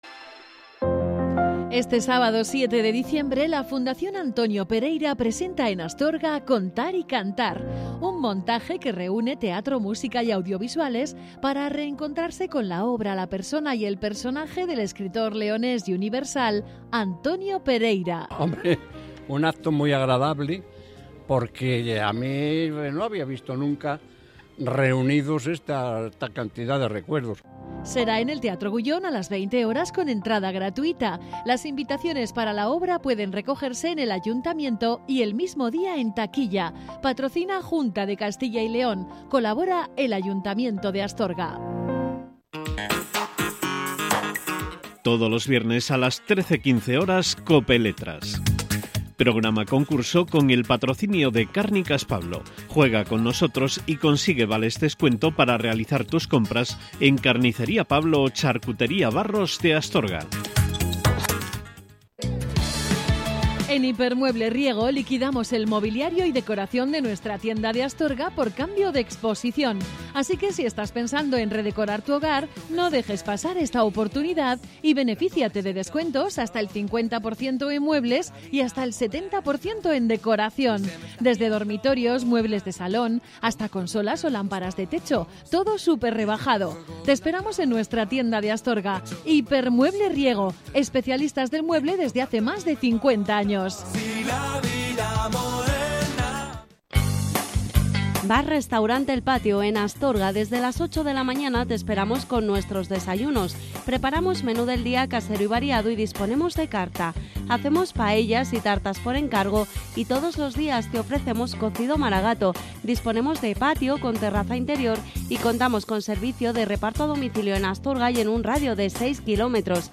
«Contar y cantar» en el teatro Gullón de Astorga
Representación del espectáculo titulado «Contar y cantar», sobre el poeta y cuentista de Villafranca del Bierzo